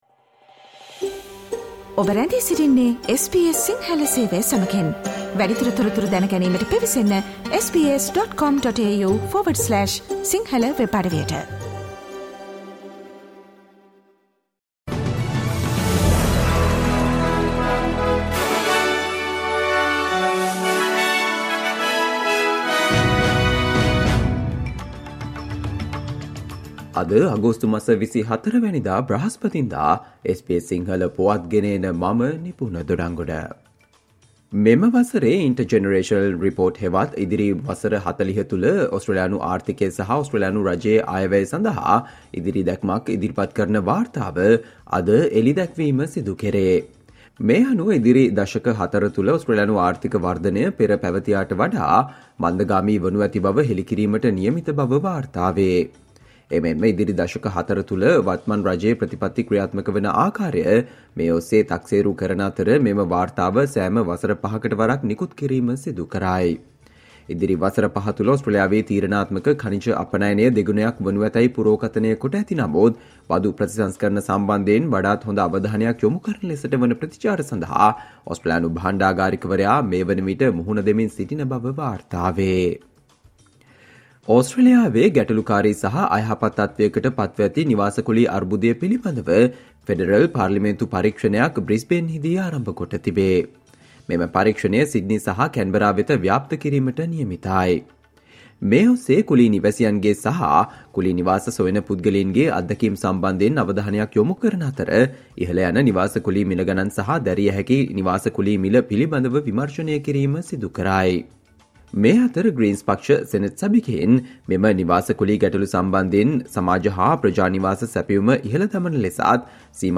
ඕස්ට්‍රේලියාවේ පුවත් සිංහලෙන්, විදෙස් සහ ක්‍රීඩා පුවත් කෙටියෙන් - සවන්දෙන්න, අද - 2023 අගෝස්තු මස 24 වන බ්‍රහස්පතින්දා SBS ගුවන්විදුලියේ ප්‍රවෘත්ති ප්‍රකාශයට